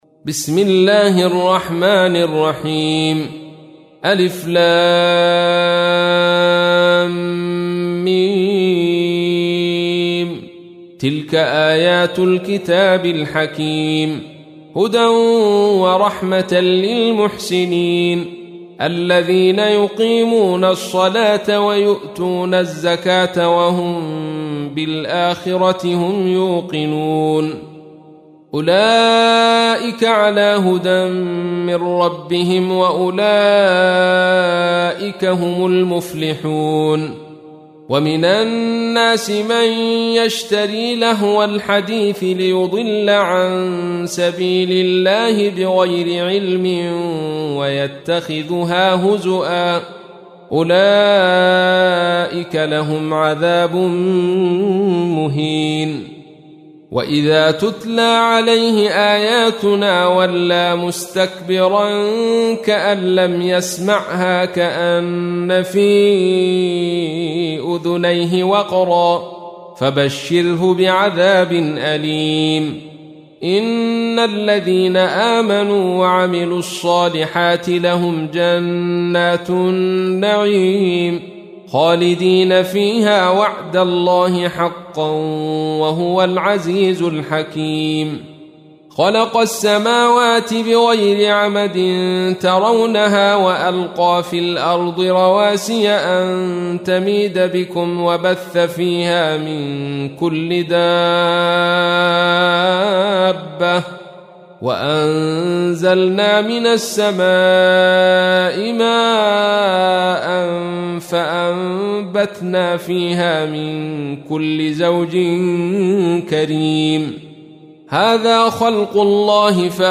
تحميل : 31. سورة لقمان / القارئ عبد الرشيد صوفي / القرآن الكريم / موقع يا حسين